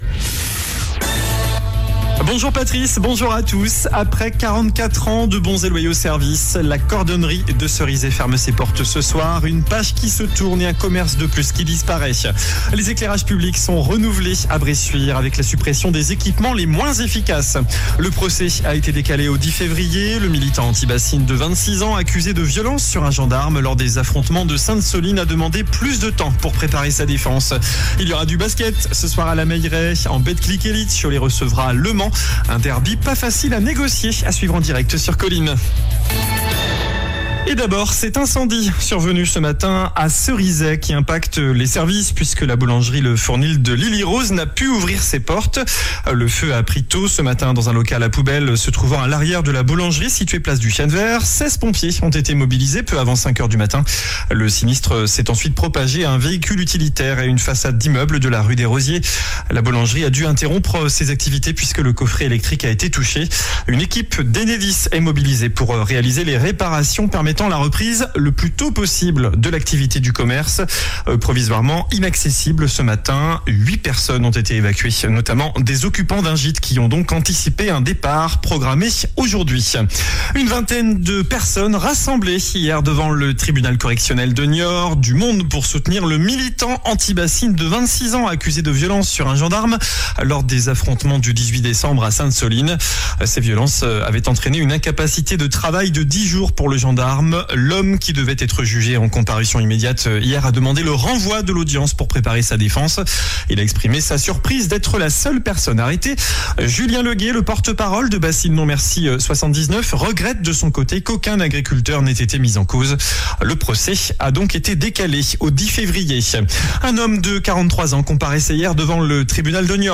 JOURNAL DU VENDREDI 27 DECEMBRE ( MIDI )